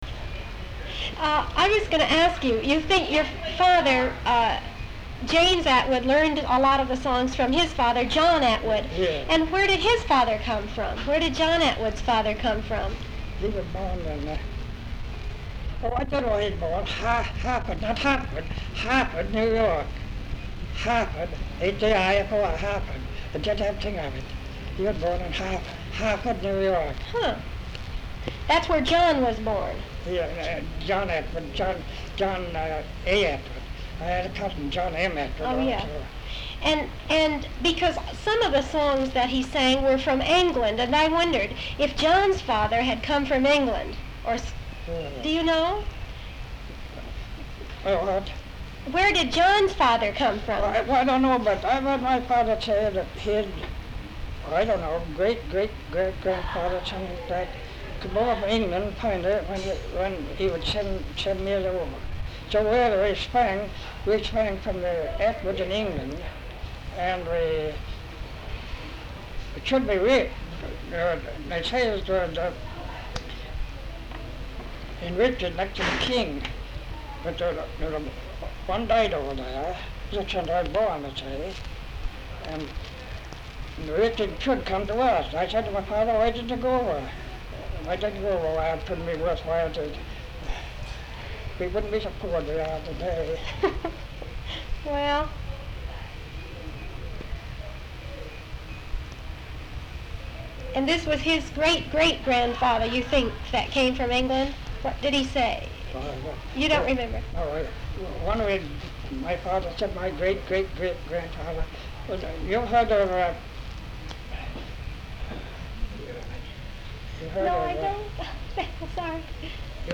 sound tape reel (analog)